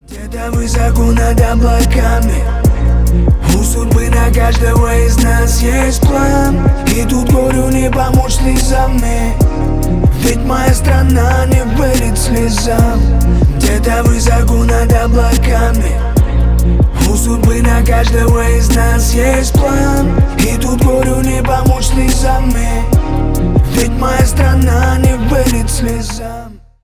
Рэп и Хип Хоп
тихие # спокойные